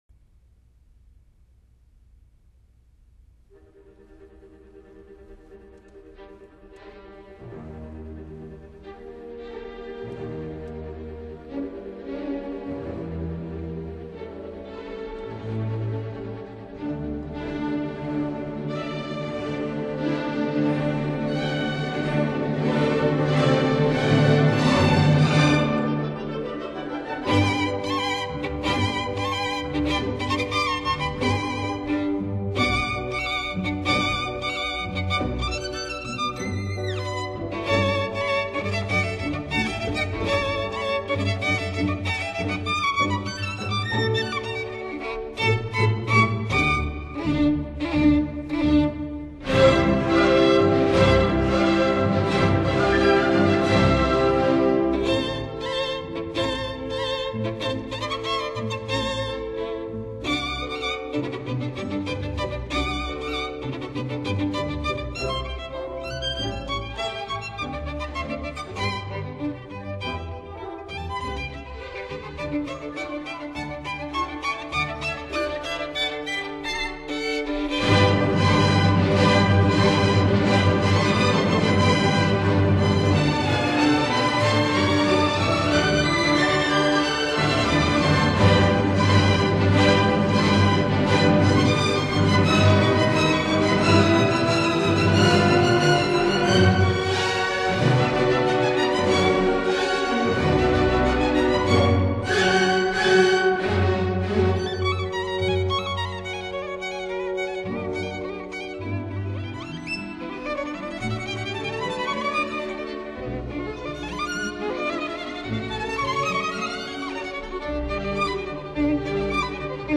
，德國浪漫樂派作曲家、指揮家、音樂教育家。